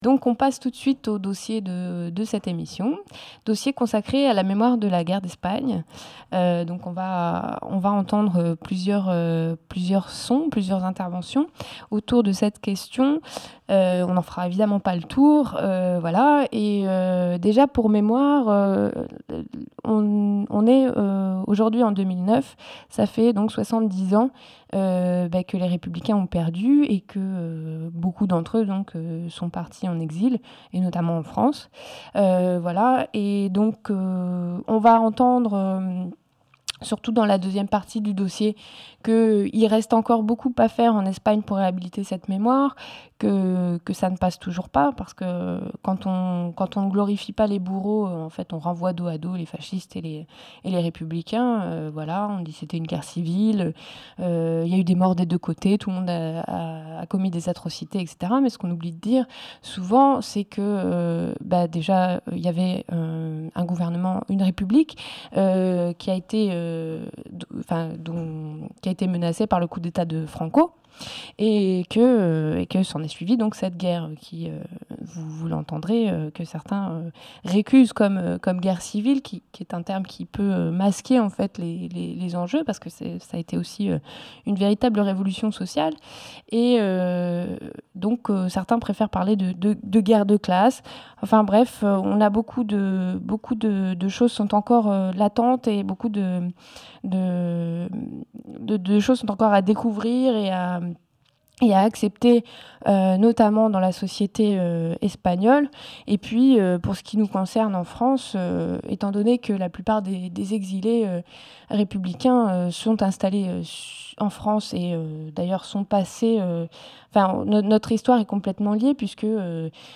interview
Et des extraits d'une discussion autour de la mémoire de la guerre d'Espagne, qui a eu lieu à Toulouse lors du festival Origines Contrôlées, le 10 novembre 2008.